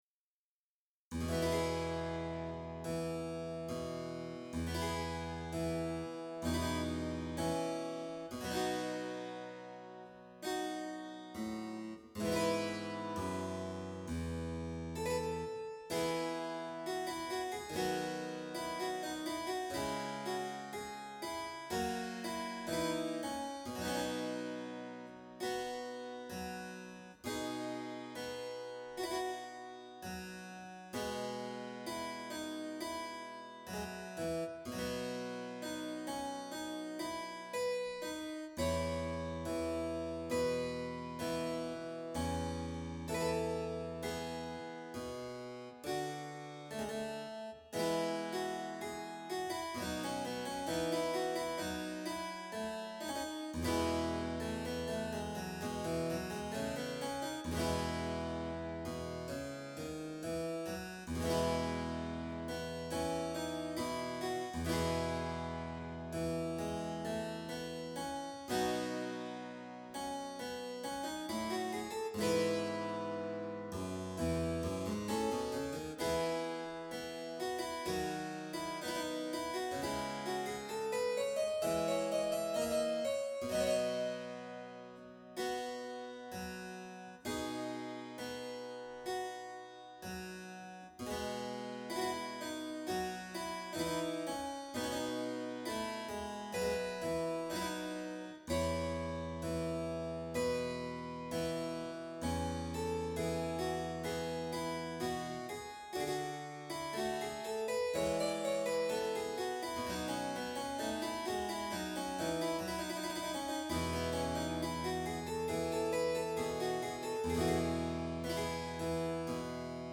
ヴァージナル曲集
Amarilli パヴァーヌとガリアルド セットで踊られる舞曲でゆっくりしたほうです。